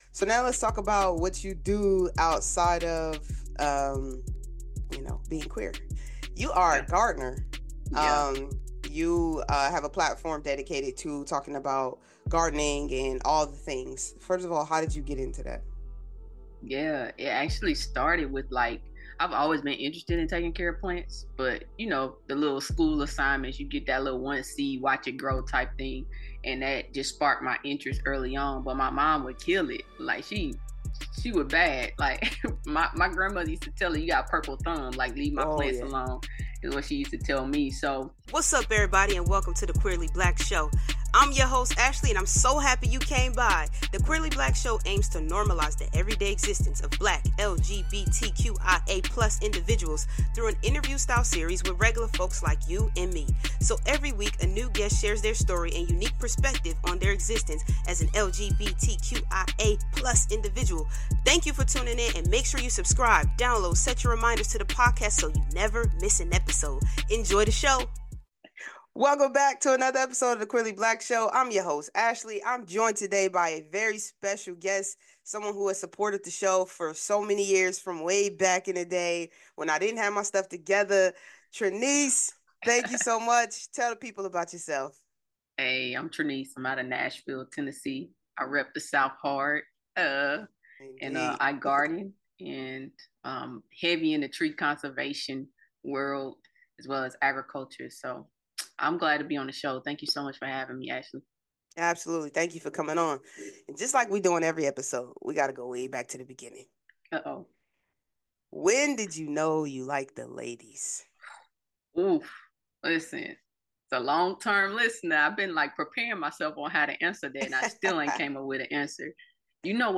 Dive into their candid conversation covering various aspects of being queer in the South and beyond.